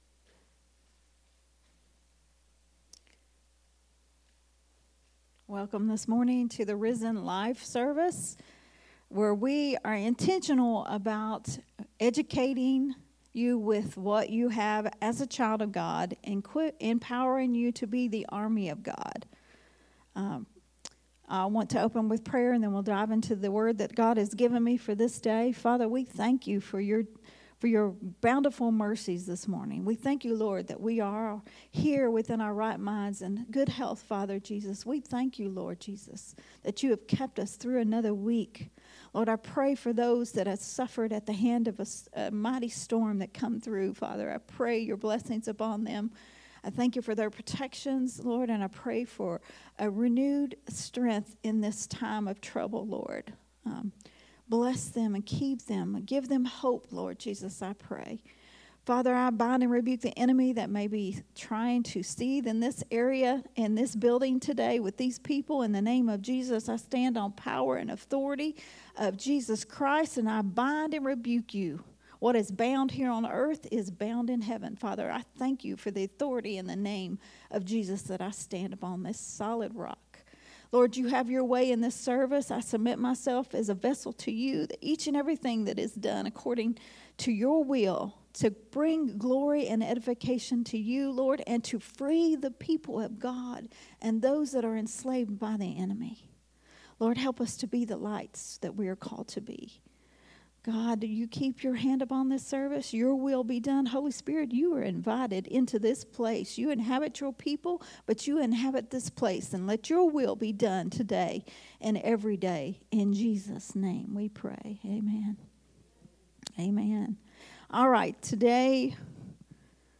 a Sunday Morning Risen Life teaching
recorded at Unity Worship Center on Sunday September 29th, 2024.